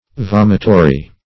Vomitory \Vom"i*to*ry\, a. [L. vomitorious.]